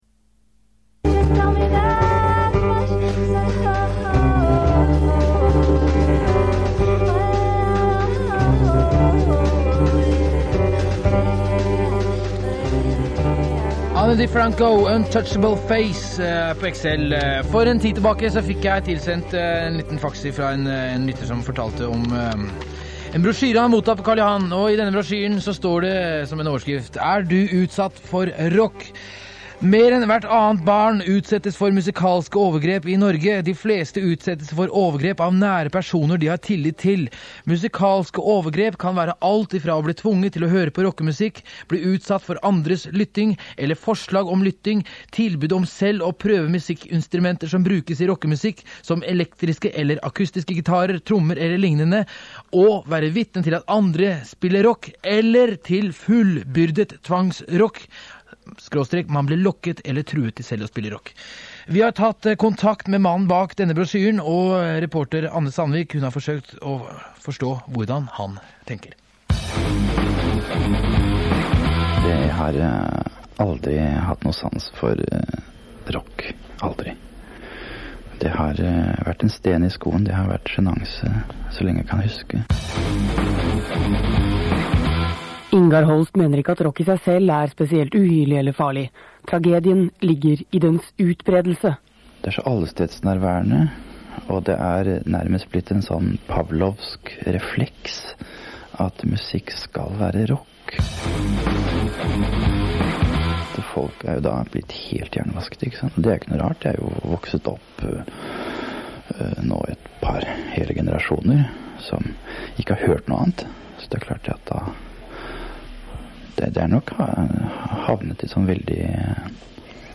Innslag i NRK P3 «XL»